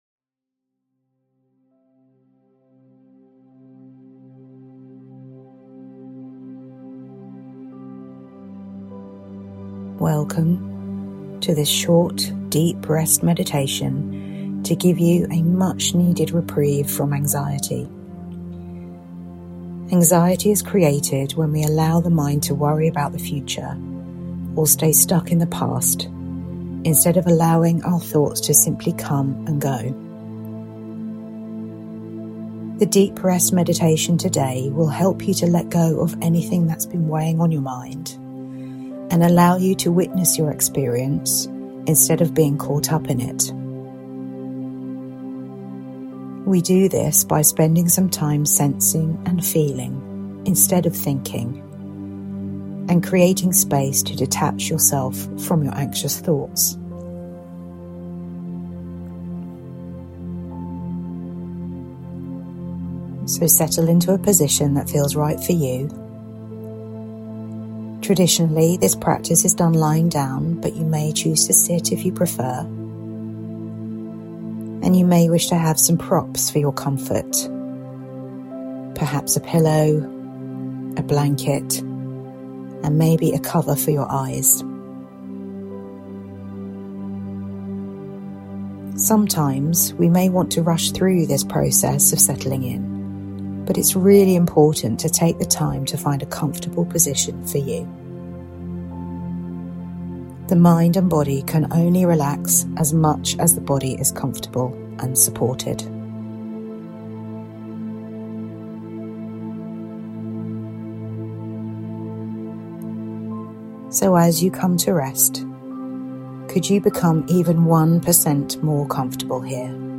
Deep Rest Meditation to calm anxiety